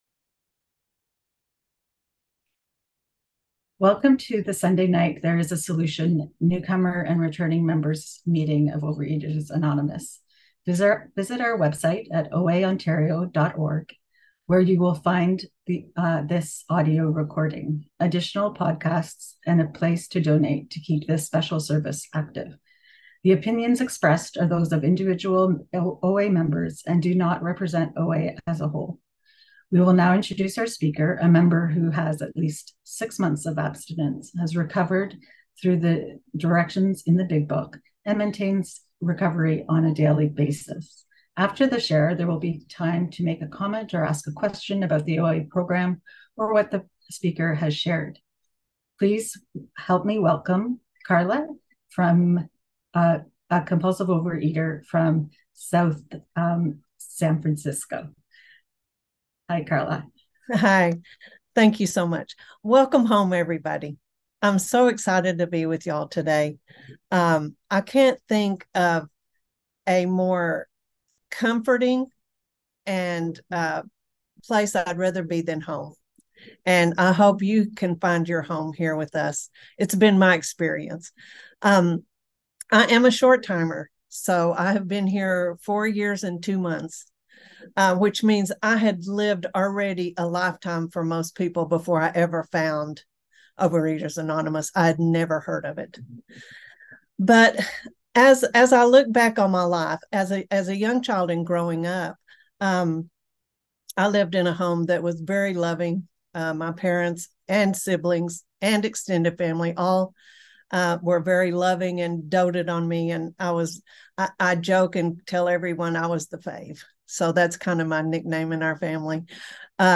OA Newcomer Meeting